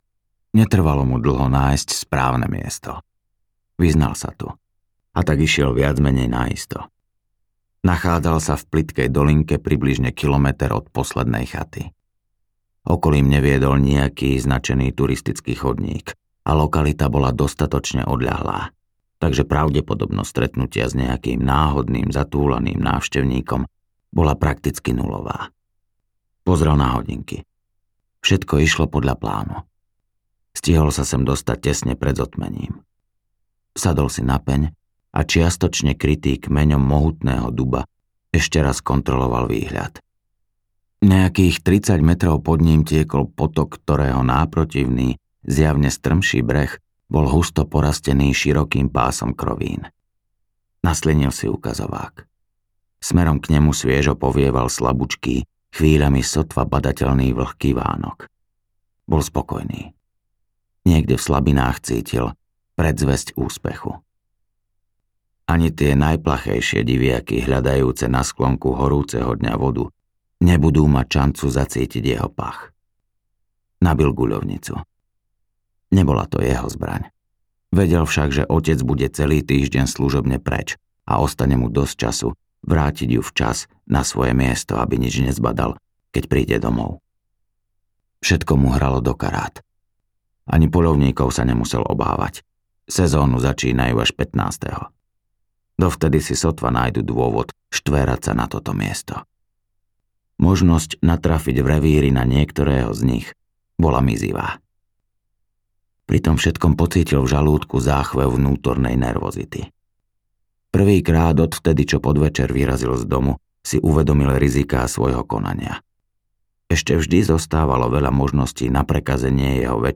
Tiene minulosti audiokniha
Ukázka z knihy